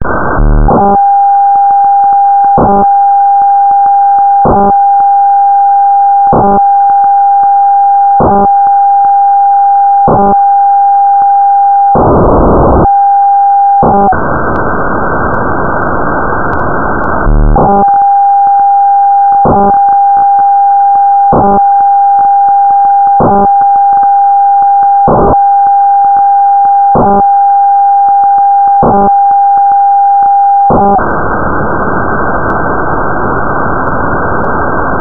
this file of demodulated FLEX 2-level paging.